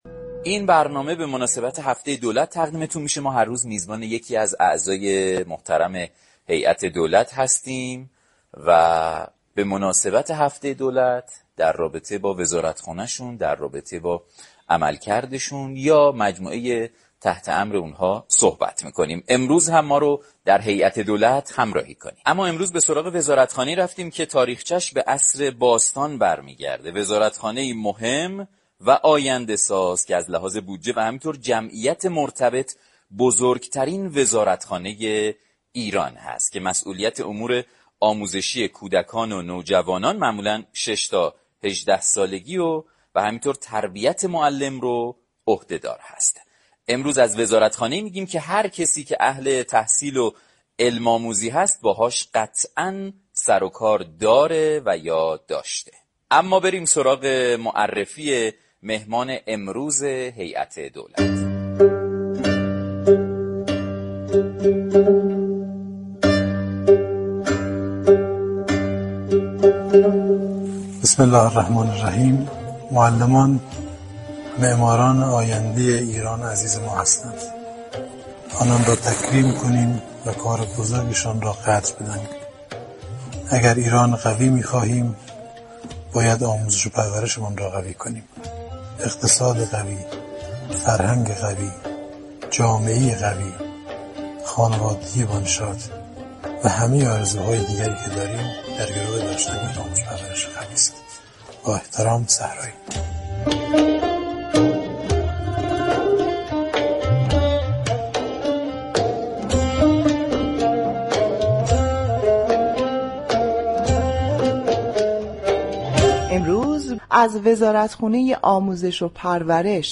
به گزارش شبكه رادیویی ایران، رضا مراد صحرایی وزیر آموزش و پرورش در دولت سیزدهم، هفتادمین وزیر آموزش و پرورش در تاریخ كشور و هفدمین وزیر بعد از انقلاب، در برنامه «هیئت دولت » رادیوایران در خصوص تحولات و چرخش های نوین نكاتی را مطرح كرد.